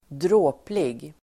Ladda ner uttalet
dråplig adjektiv, screamingly (killingly) funny Uttal: [²dr'å:plig] Böjningar: dråpligt, dråpliga Definition: mycket rolig Exempel: en dråplig historia (a screamingly funny story) hilarious adjektiv, dråplig